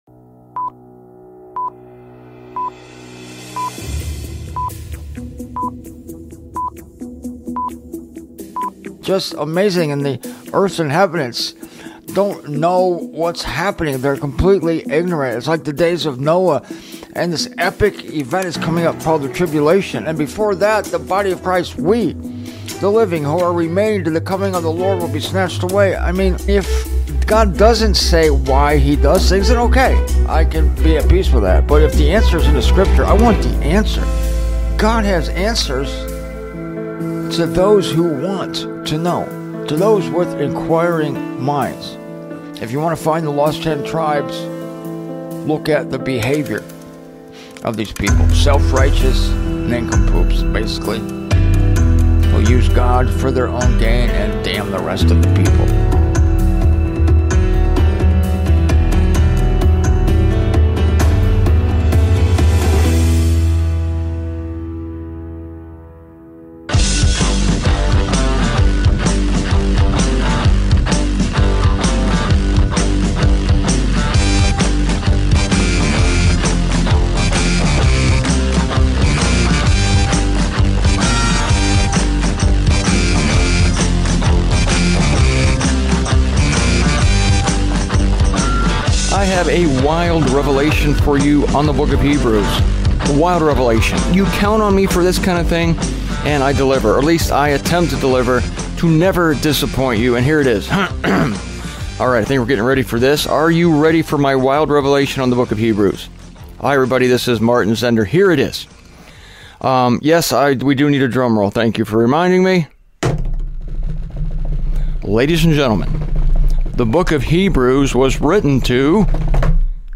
You will hear three shows today, each one a little less than 15 minutes in length. Hebrews is a vital letter—helpful and practical—written to the Jews sometime between 64 and 69 AD.